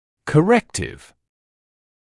[kə’rektɪv][кэ’рэктив]корректирующий, поправочный; корректировка